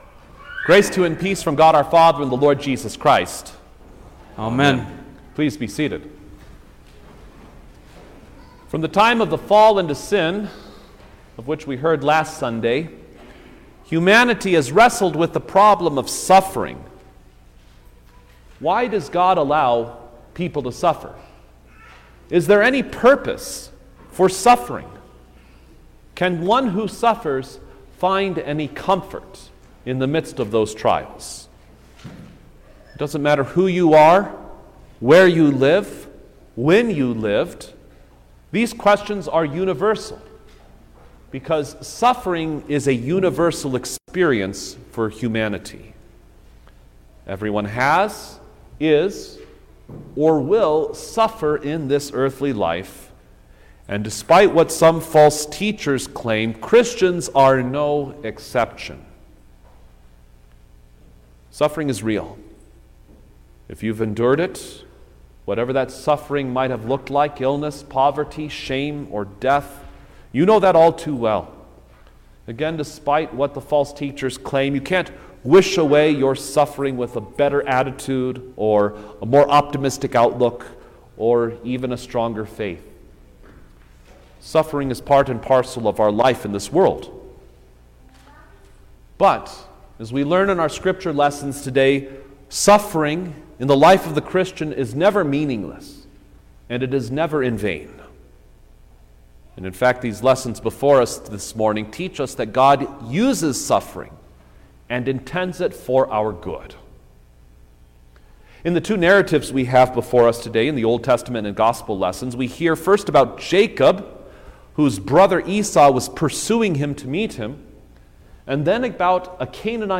March-5_2023_Second-Sunday-In-Lent_Sermon-Stereo.mp3